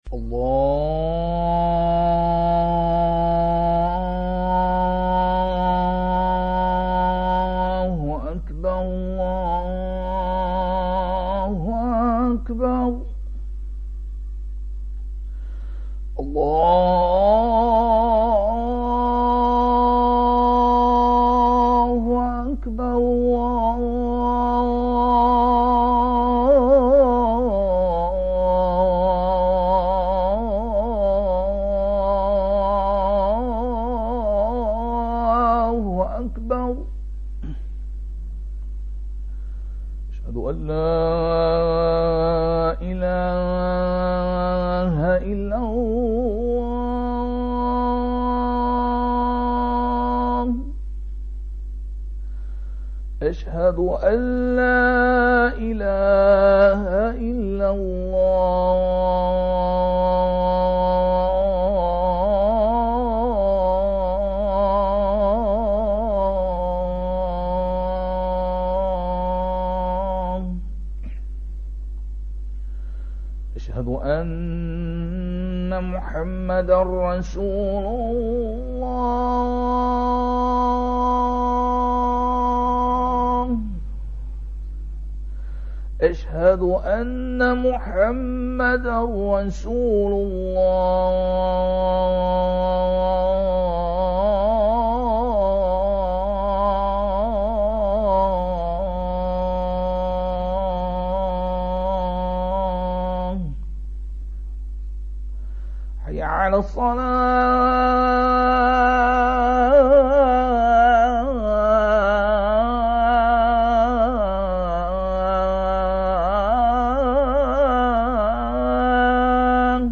When the time for any of the 5 obligatory prayers comes, a man (called a mu-adh-dhin) and calls aloud these words to summon muslims in the neighbourhood of the mosque to come to prayer:
Azaan by a brother
brother azaan.mp3